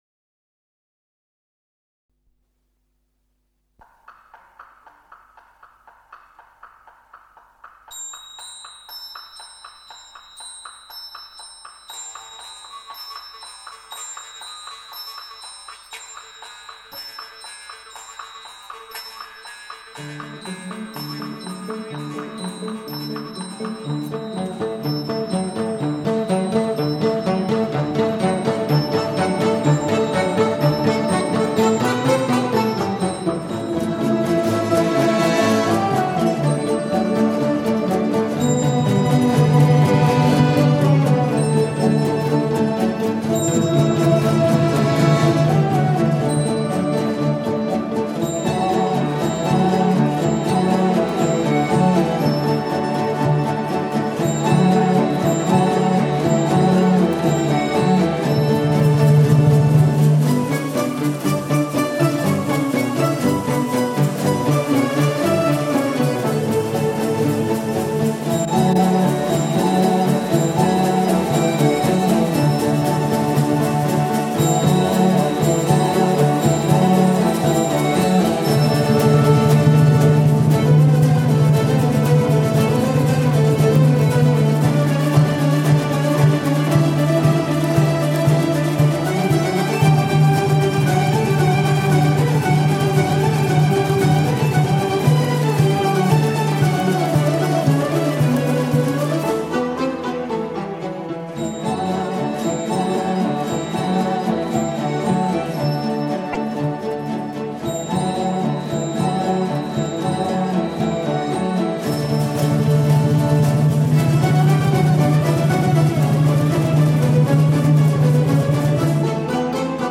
это произведение в жанре казахской народной музыки